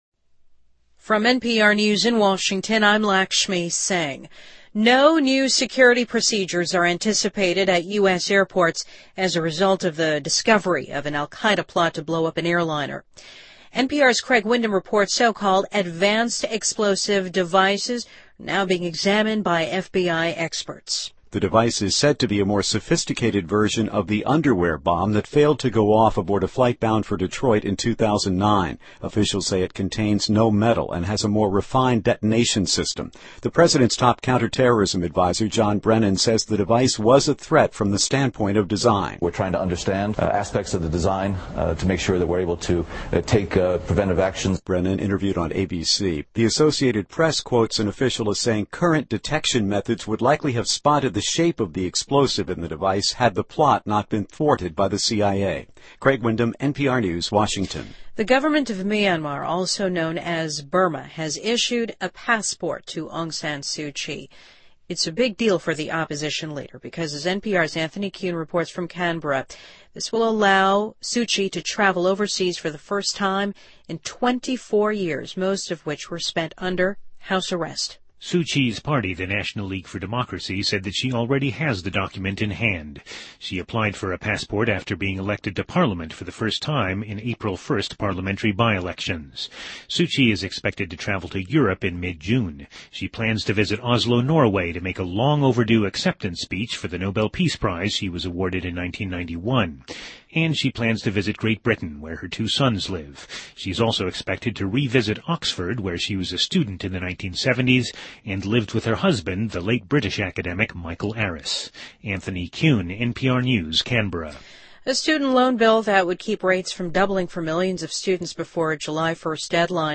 NPR News,2012-05-09